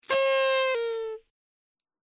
tada.wav